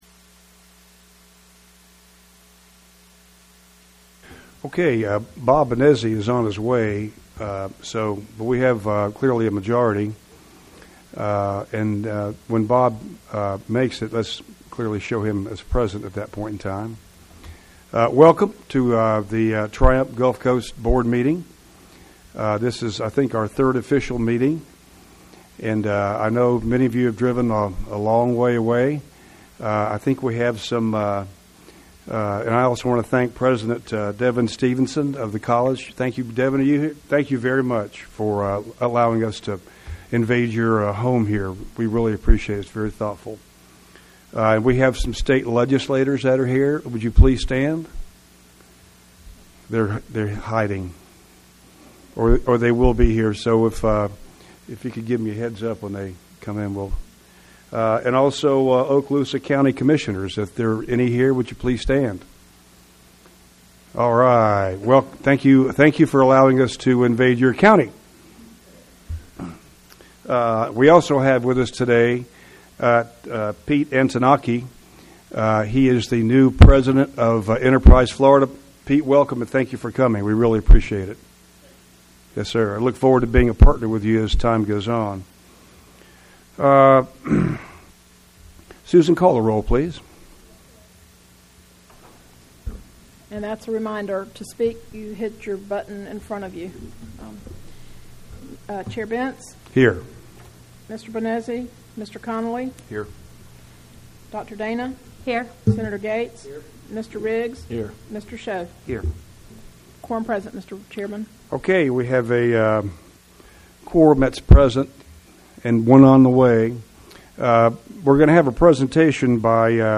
The Board of Triumph Gulf Coast, Inc., met at 2:00 p.m., CT on Tuesday, October 10, 2017. The meeting was held in the cafeteria (lower level) of the Student Service Center (Building 400), Northwest Florida State College, 100 College Boulevard, Niceville, Florida 32578.